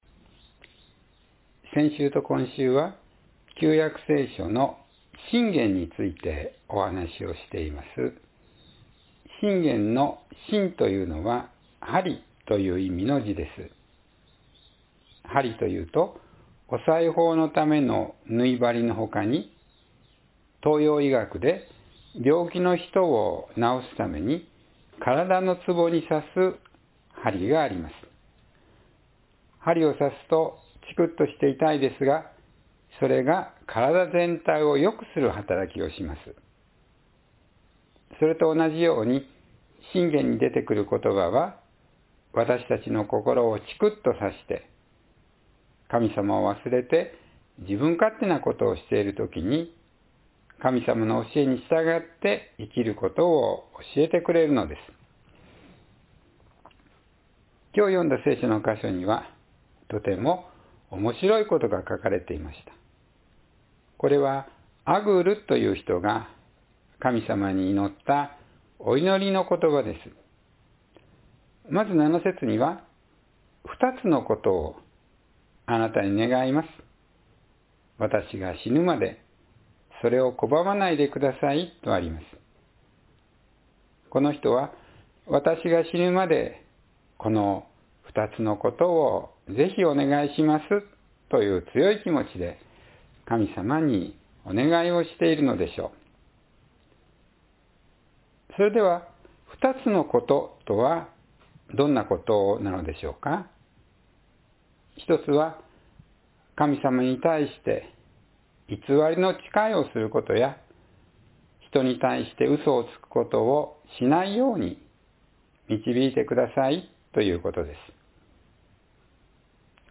貧しくもせず金持ちにもせず“Neither Poor nor Rich”（2024年11月17日・子ども説教） – 日本キリスト教会 志木北教会